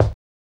SOFT CRACKLY.wav